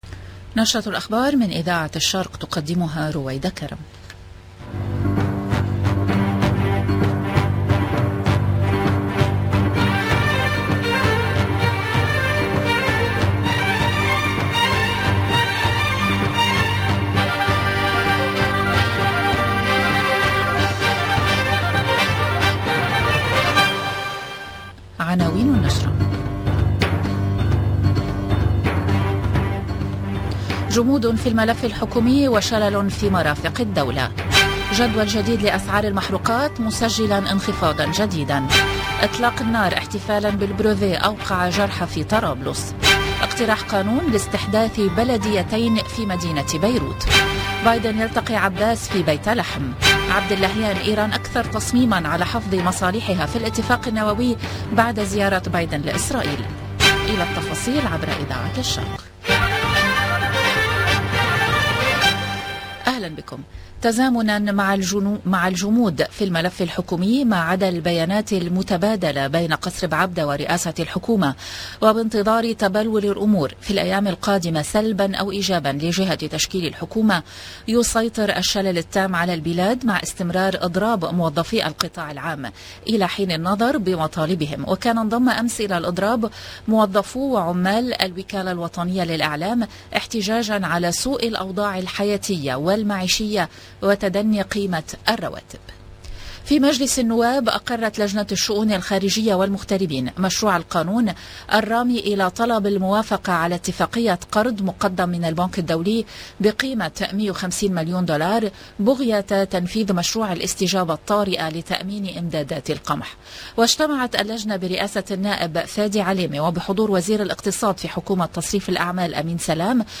LE JOURNAL DU LIBAN DE LA MI-JOURNEE DU 15/07/22